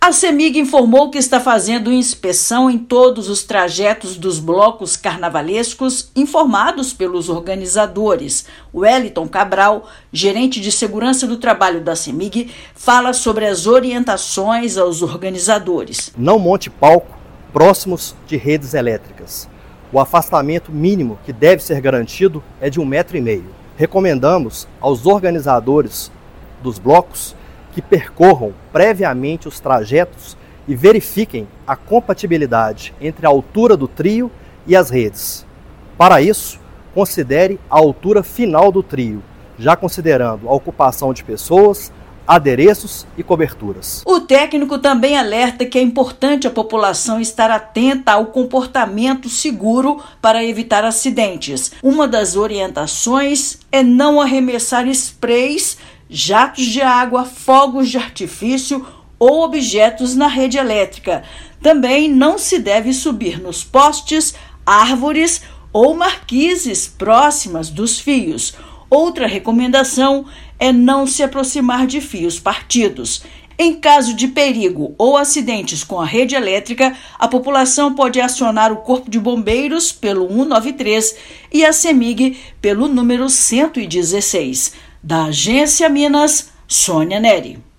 Empresa trabalha com diversos preparativos e precauções para garantir que a festa mais animada do ano seja livre de acidentes com eletricidade. Ouça matéria de rádio.